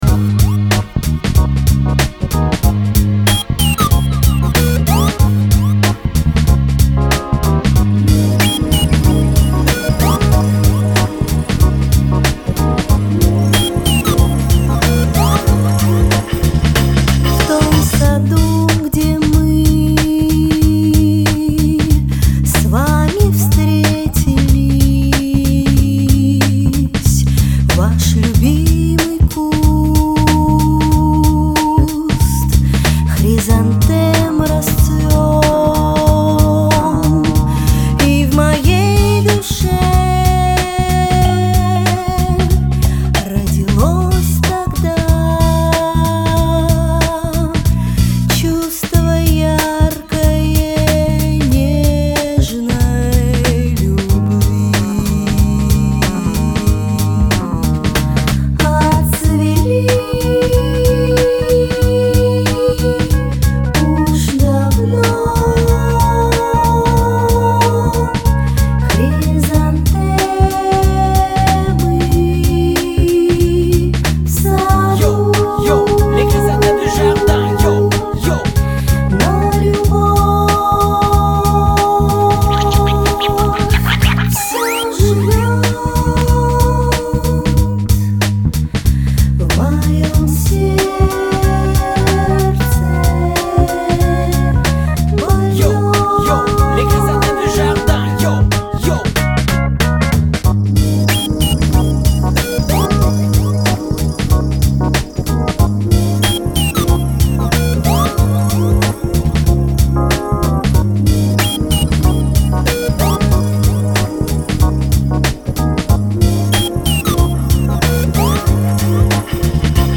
джаз, поп-музыка